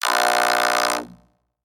robotscream_6.ogg